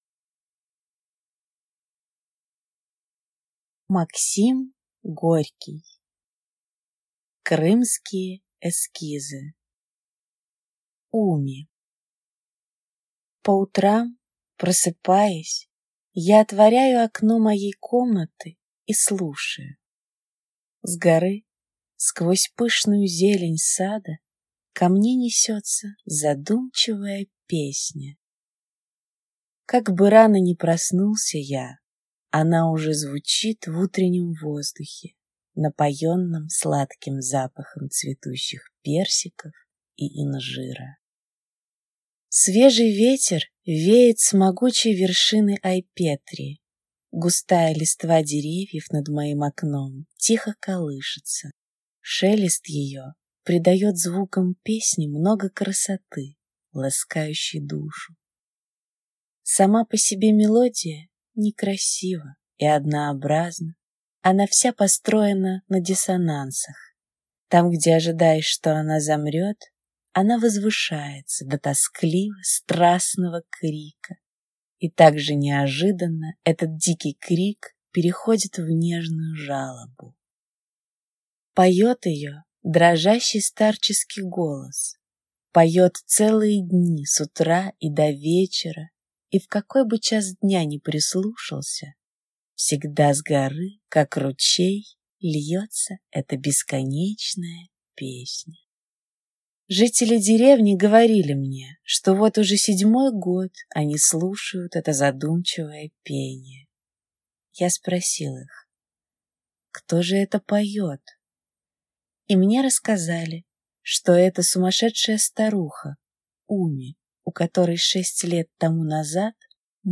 Аудиокнига Крымские эскизы | Библиотека аудиокниг